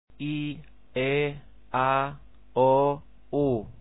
Each vowel is pure, without glides, distinct from the others, and never reduced.
a sound file for these too (me saying the vowels /i e a o u/) [8 KB MP3 file].
phon_ieaou.mp3